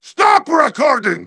synthetic-wakewords
ovos-tts-plugin-deepponies_Heavy_en.wav